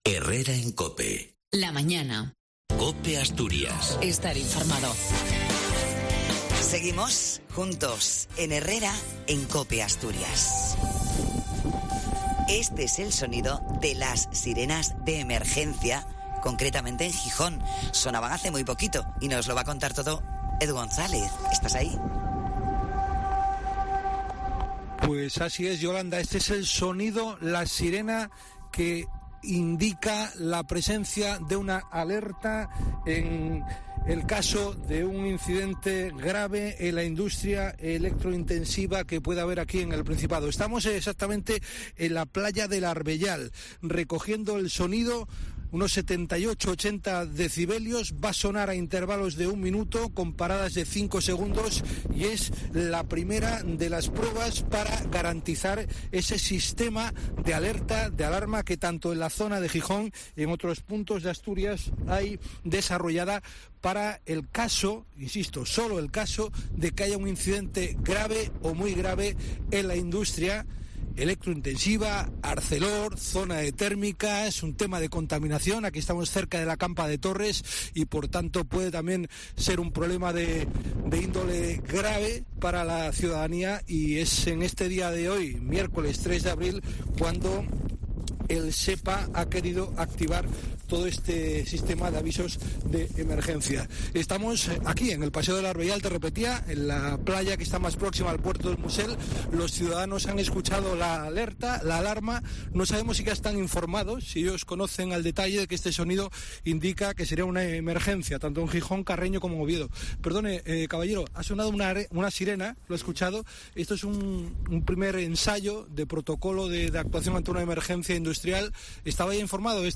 Sirenas de Alerta
Los vecinos de la Zona Oeste de Gijón, Carreño y Trubia, se han visto sorprendidos este miércoles con un ruido intenso de bocinas, que formaban parte de un simulacro para advertir a la población de un accidente industrial grave.
Los sonidos se repitieron en intervalos de 1 minuto, con espacios de silencios entre uno y otro, de cinco segundos. Sonidos largos y prolongados, se iban alternando con intermitencias sonoras.